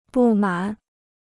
不满 (bù mǎn) Free Chinese Dictionary